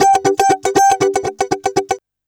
120FUNKY07.wav